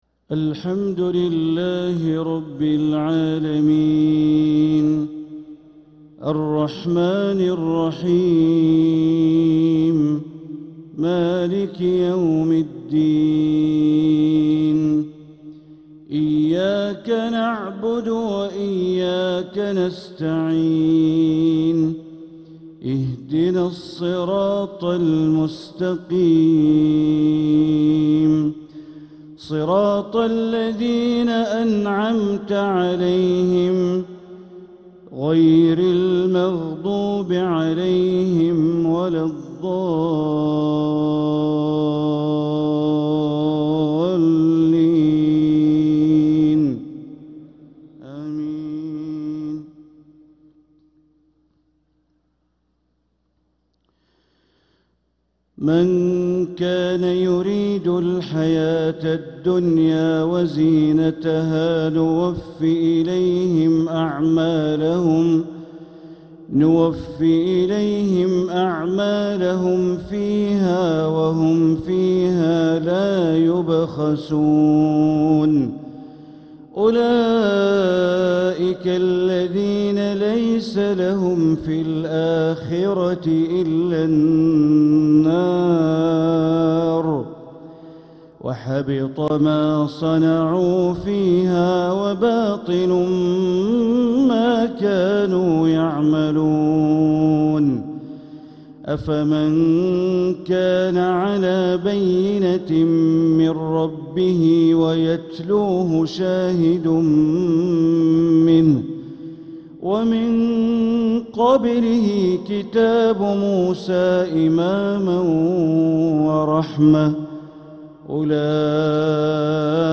Maghrib prayer from Surat Hood and al-Isra 7-2-2025 > 1446 > Prayers - Bandar Baleela Recitations